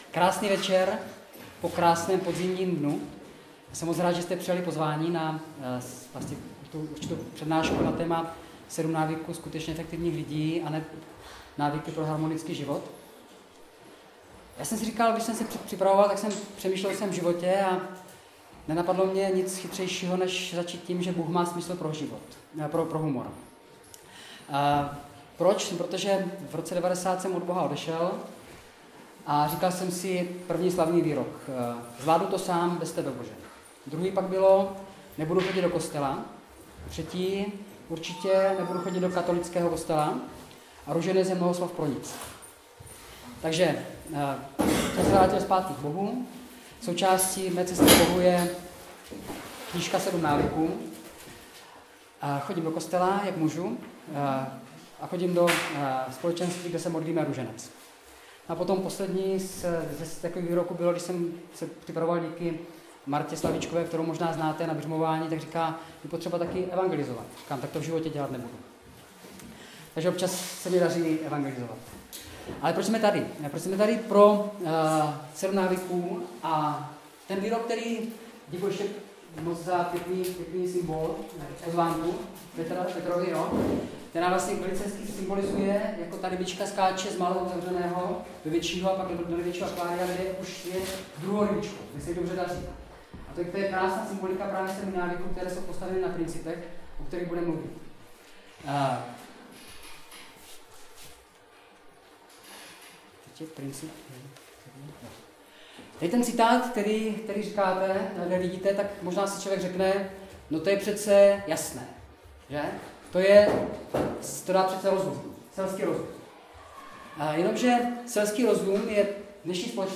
Záznam přednášky v mp3: